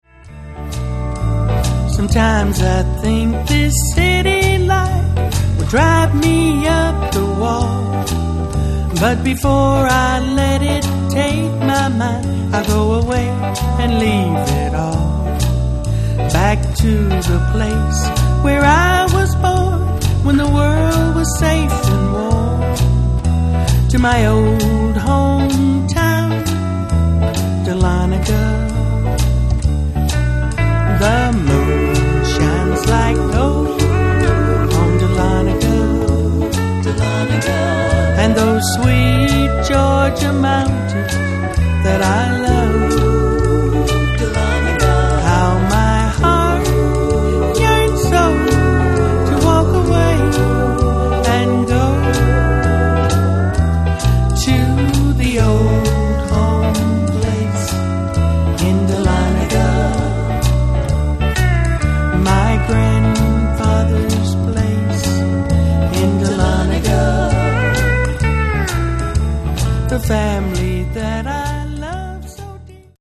banjo
fiddle
pedal steel & dobro
tenor sax